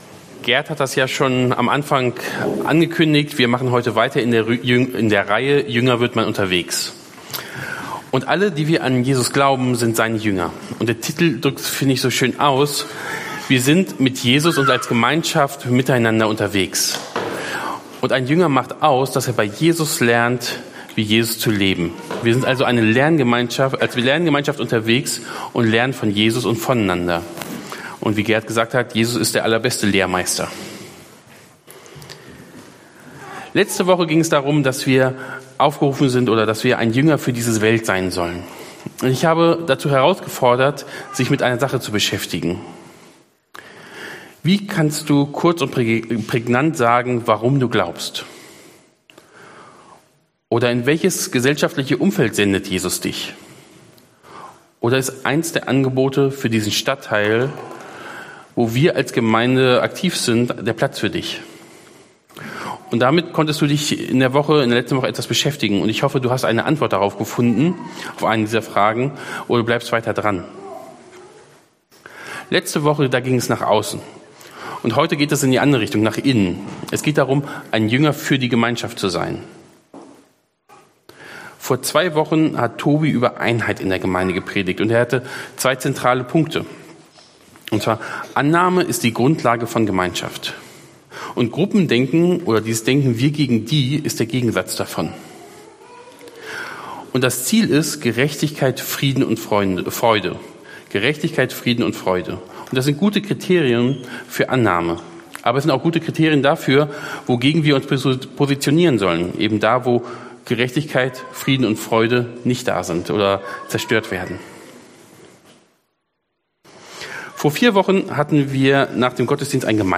Passage: Philipper 2 Dienstart: Predigt